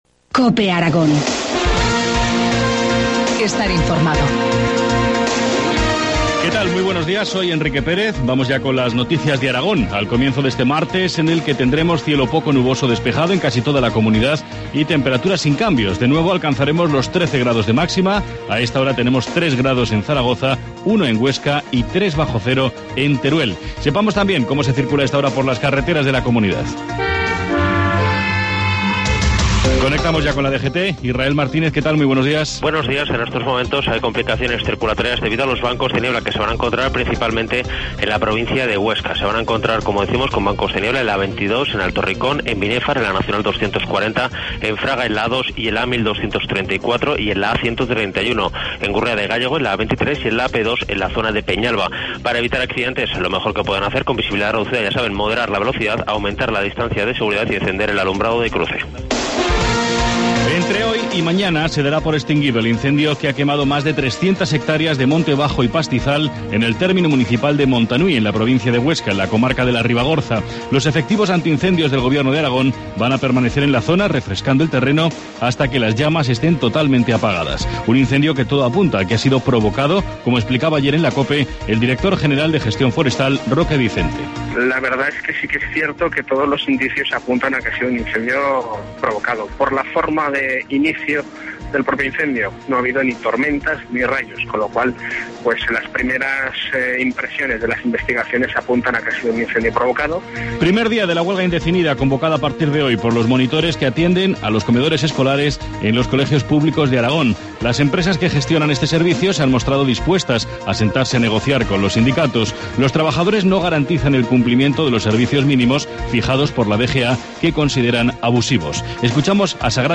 Informativo matinal, martes 8 de enero, 7.25 horas